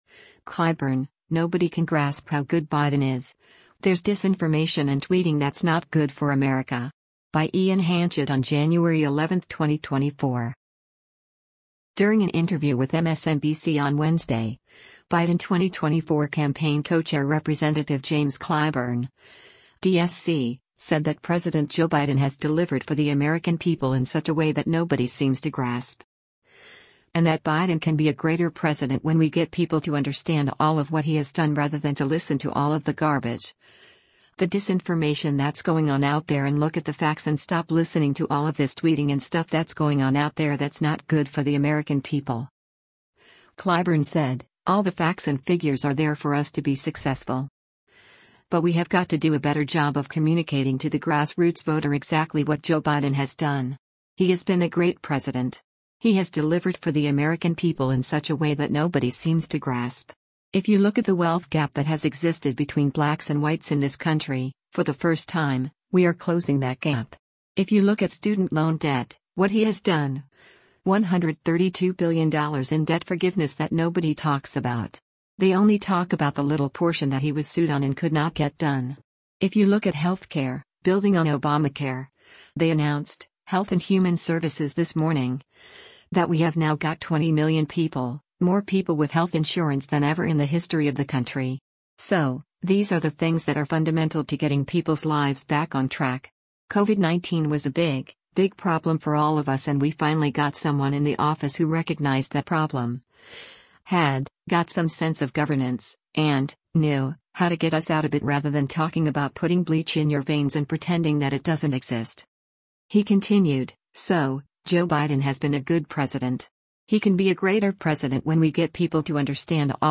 During an interview with MSNBC on Wednesday, Biden 2024 Campaign Co-Chair Rep. James Clyburn (D-SC) said that President Joe Biden “has delivered for the American people in such a way that nobody seems to grasp.” And that Biden “can be a greater President when we get people to understand all of what he has done rather than to listen to all of the garbage, the disinformation that’s going on out there and look at the facts and stop listening to all of this tweeting and stuff that’s going on out there that’s not good for the American people.”